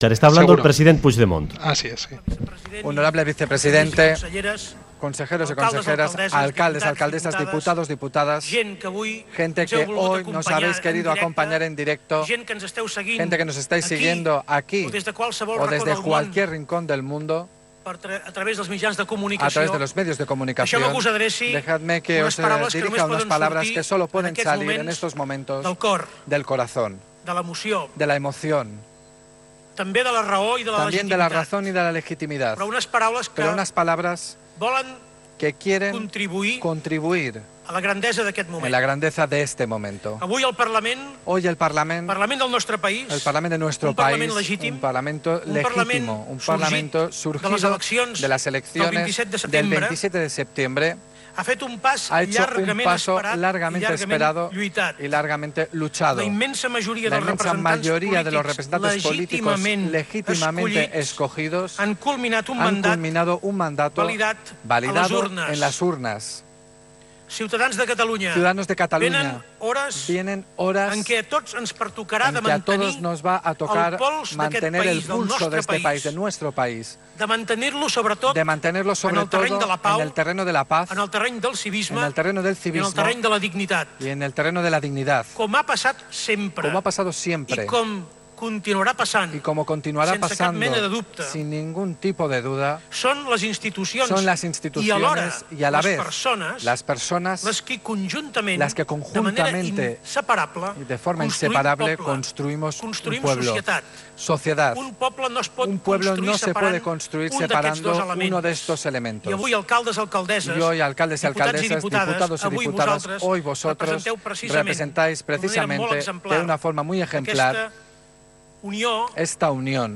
Audio: Declaraciones de Carles Puigdemont 'son las instituciones y a la vez las personas, de manera conjunta e inseparable, las que construimos un pueblo'.